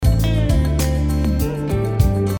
Как такое сделать (гитара)
Попробованы Ефимов, Шреддедж, Ренегад - не вышло(( Есть артикуляции по типу до-ре-до или до-си-до, а надо ми-ре-до-си-до